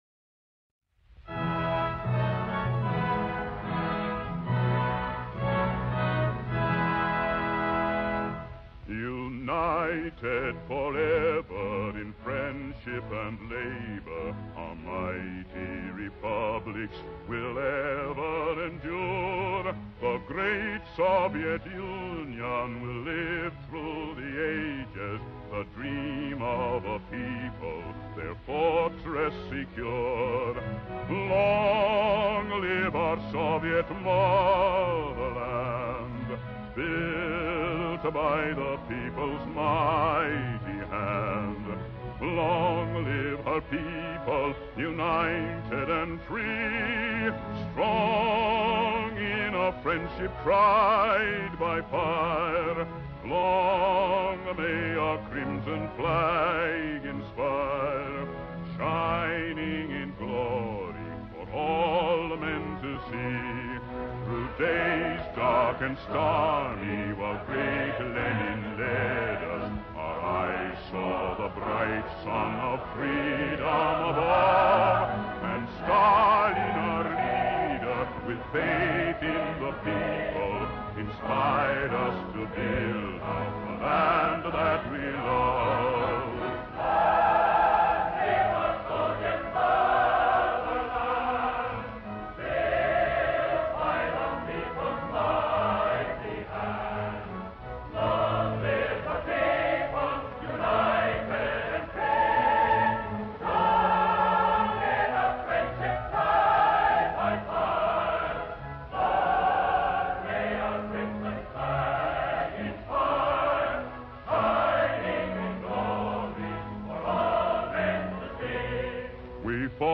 national anthem